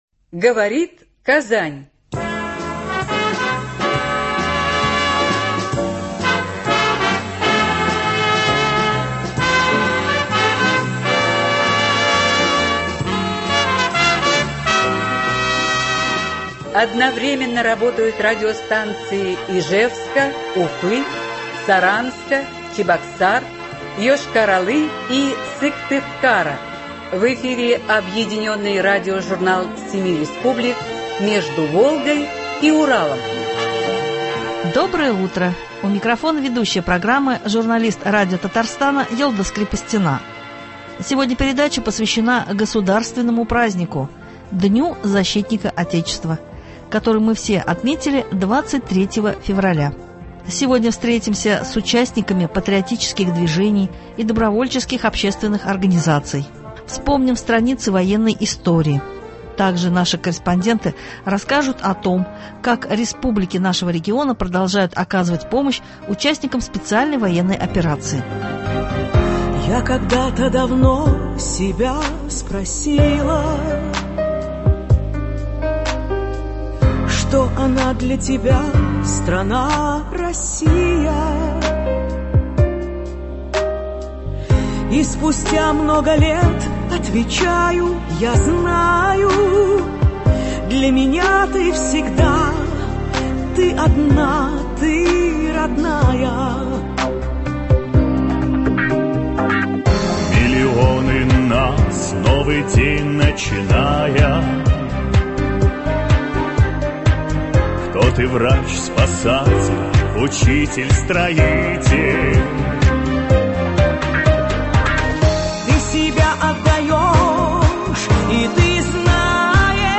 Объединенный радиожурнал семи республик.